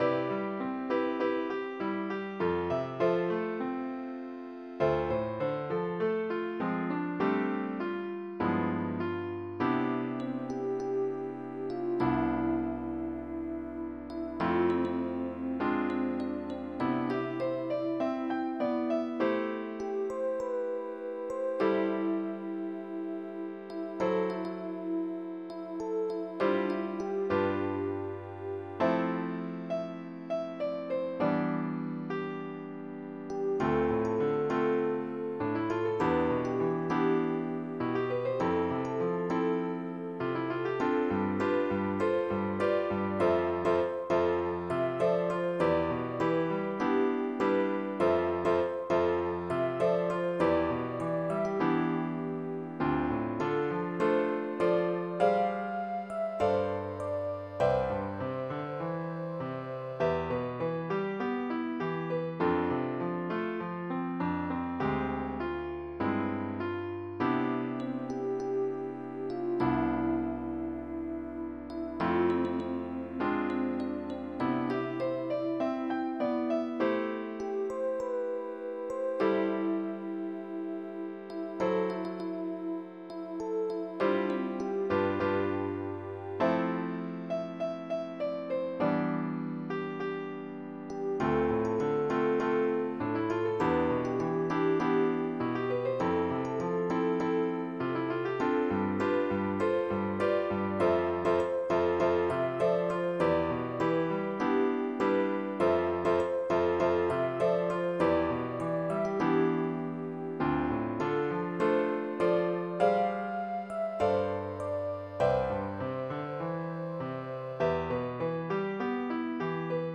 ＭP3　ピアノ演奏